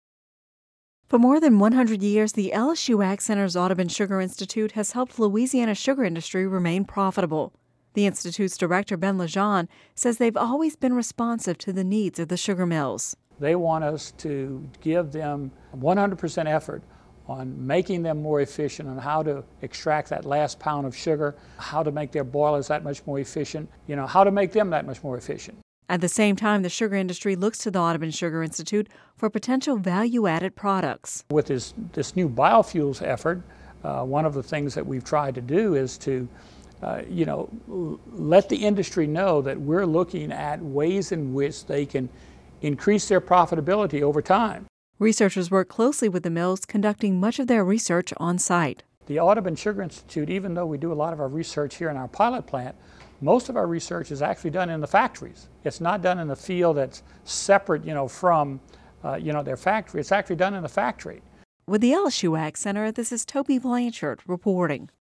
(Radio News 01/10/11) For more than 100 years, the LSU AgCenter’s Audubon Sugar Institute has helped Louisiana’s sugar industry remain profitable.